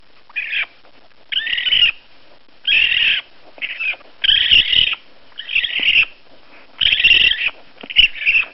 What Does a Raccoon Den Sound Like?
You may hear chittering, growling, hissing, or distinct purring sounds, especially from mothers communicating with their young.
Kits often emit high-pitched squeals or cries when hungry or distressed.
audio-baby-racoon.mp3